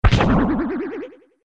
Звуки из игры Супер Марио 64 — SFX
Влияние